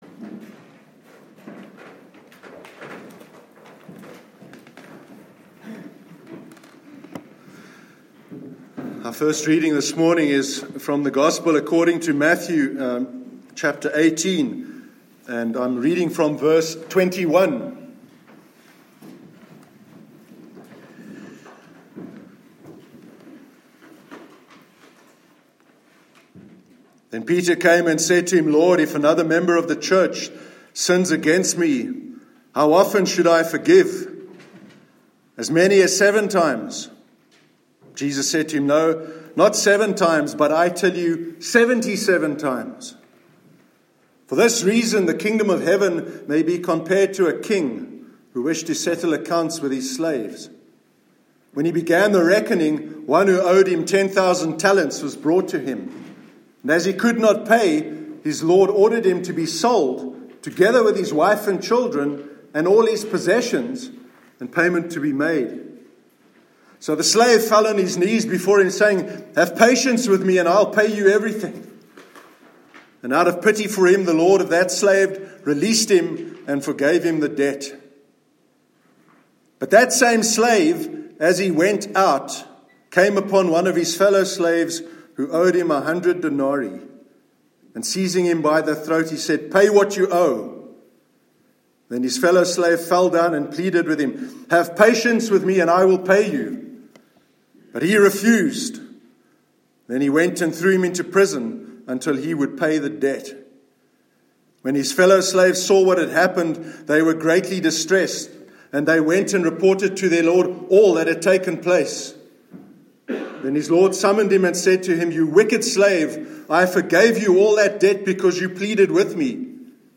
Sermon on Mercy- 18th February 2018